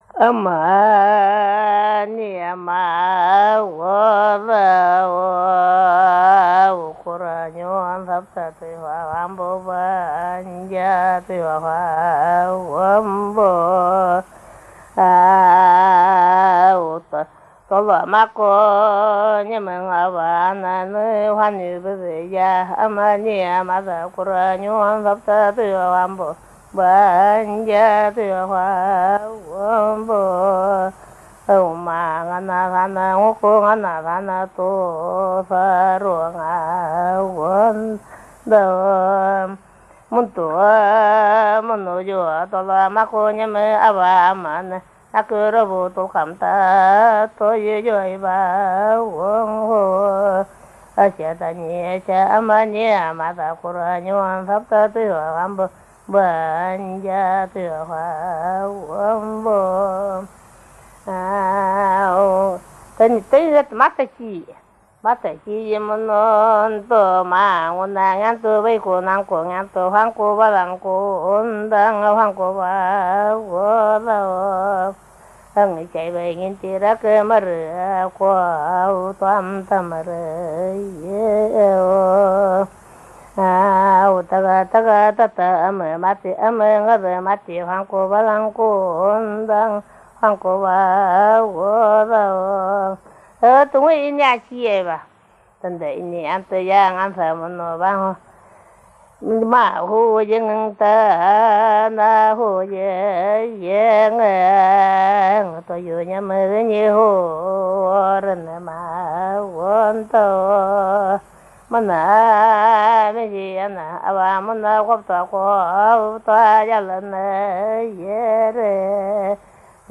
Личные песни нганасан
Для напевов балы характерна развитая мелодическая линия и обилие вставных асемантических слогов (хo, o, хe, гe, йe, э), которые подвержены распевам, в то время как слоги основного смыслонесущего текста произносятся речитативом. Главную роль в личных песнях играет мелодический фактор формообразования: в строке (реже — строфе)  начальная и/или заключительная мелодические формулы связаны с особой вокальной звукоподачей и выделены высотным или динамическим вибрато на длинном звуке.
Тип пения монологический, «для себя» (негромко, без зрелищных приемов).